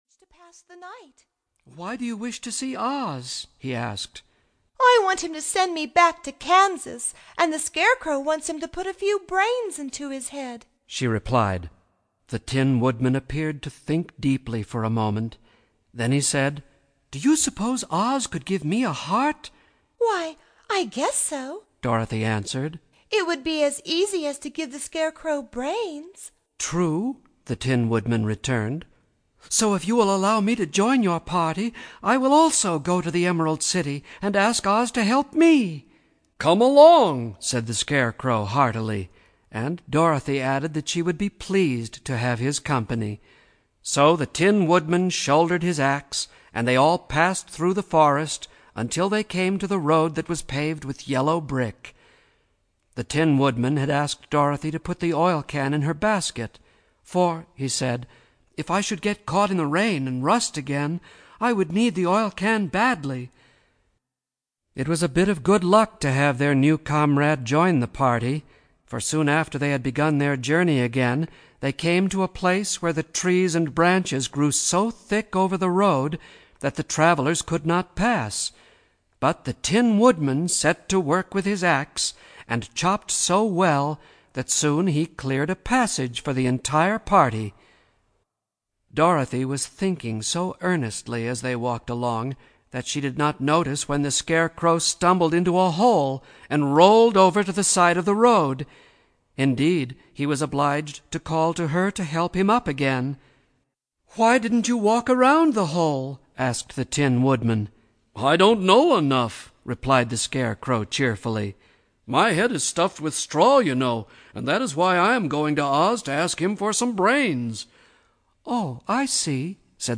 The Wonderful Wizard of Oz Audiobook
Narrator